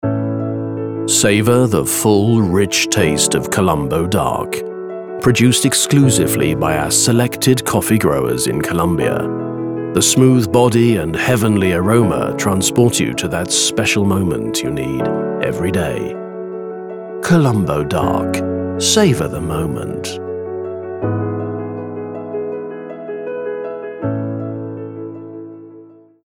Soft